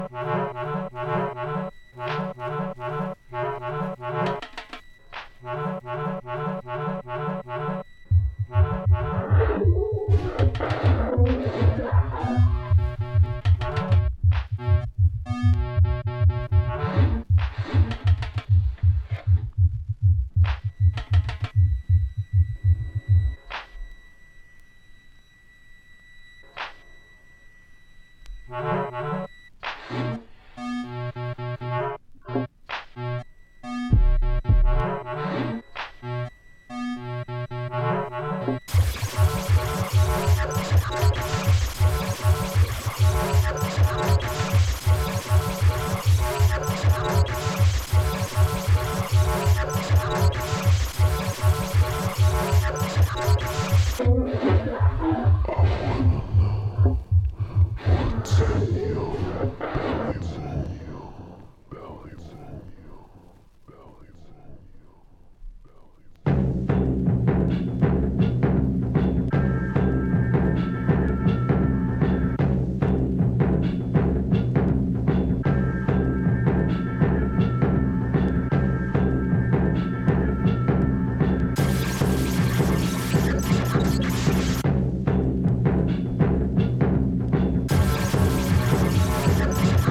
アヴァンギャルド・ミュージック・コンクレート4トラック！
AMBIENT / EXPERIMENTAL# DUB / LEFTFIELD